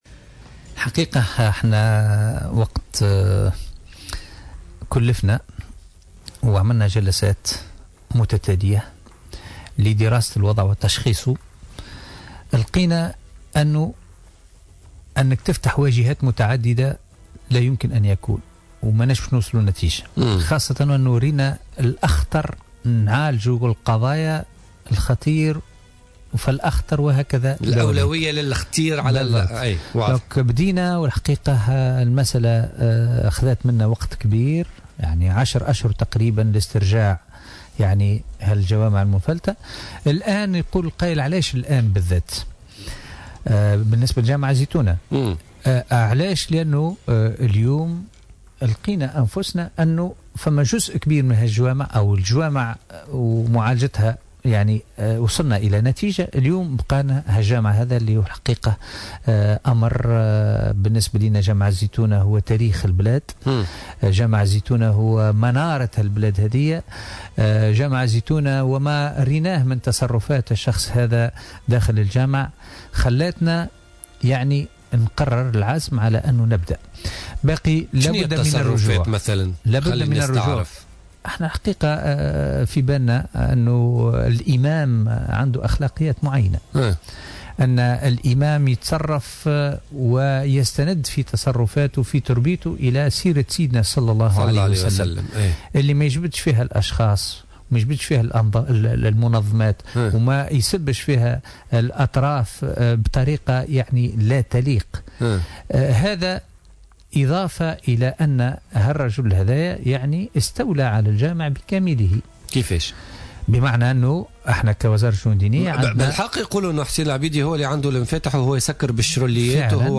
Le ministre des affaires religieuses Mounir Tlili a indiqué lors de son passage sur les ondes de Jawhara FM dans le cadre de l’émission Politica que son ministère veille à appliquer la loi et neutraliser les mosquées tunisiennes.